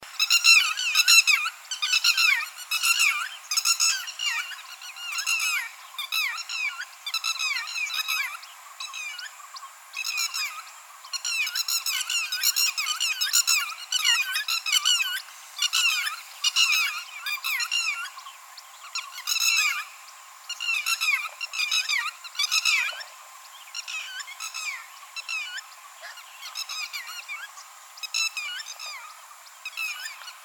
На этой странице собраны звуки чибиса — звонкие и мелодичные крики этой птицы.